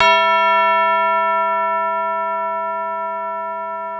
Dre-Bell 1.wav